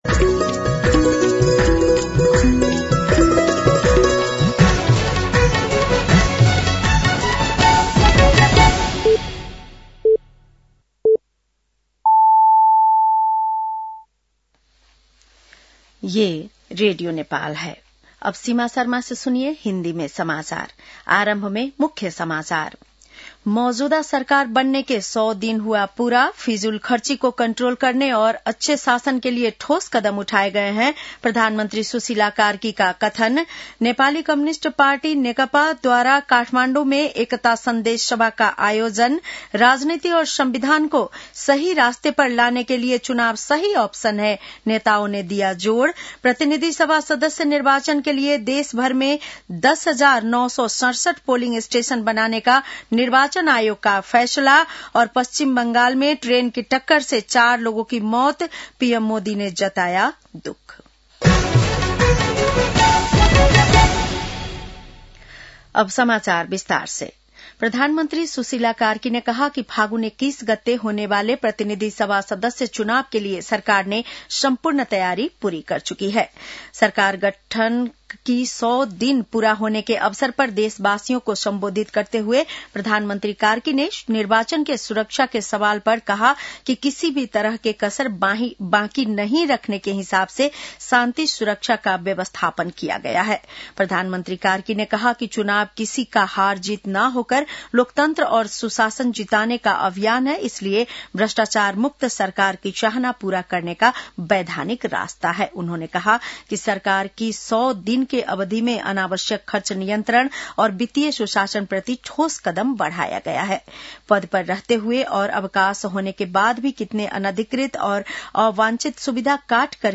बेलुकी १० बजेको हिन्दी समाचार : ५ पुष , २०८२